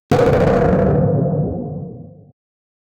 Entrance Sound Effects - Free AI Generator & Downloads
sound-of-close-cartoon-ga-nkhwig5i.wav